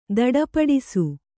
♪ daḍapaḍisu